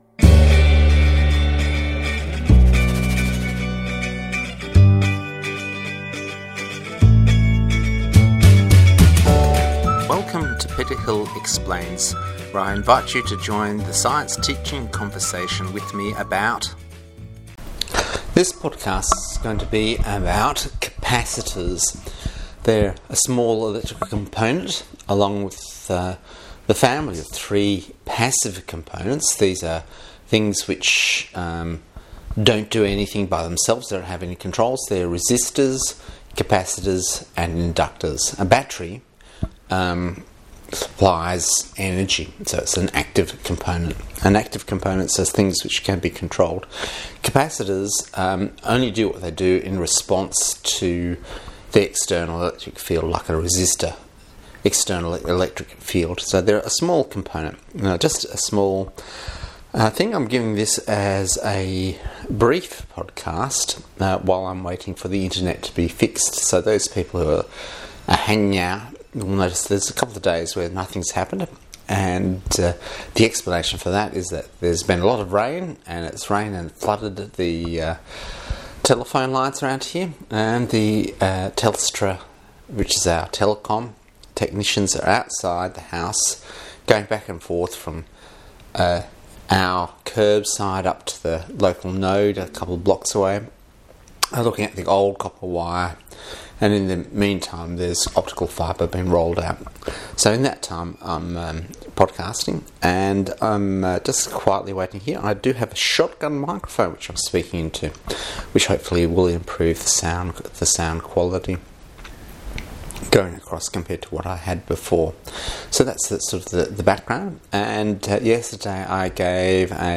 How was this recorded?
Trialing the new shot gun microphone MP4 recording MP3 recording